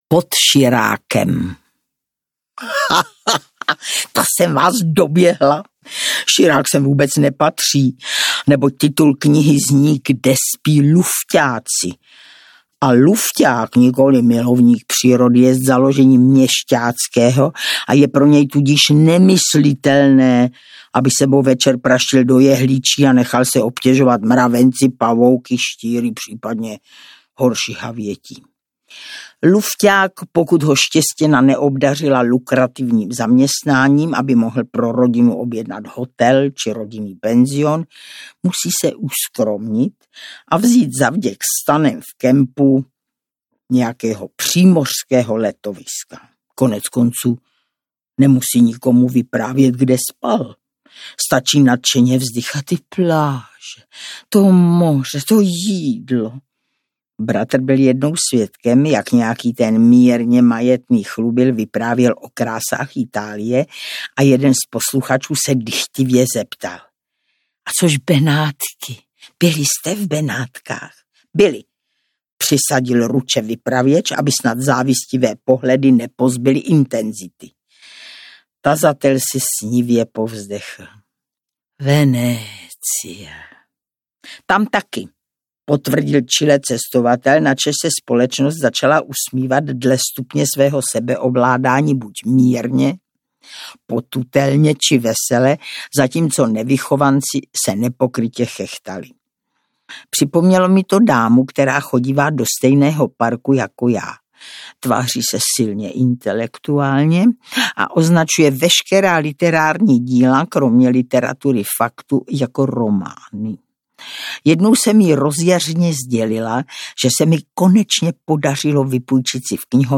Kde spí lufťáci audiokniha
Ukázka z knihy
• InterpretIvanka Devátá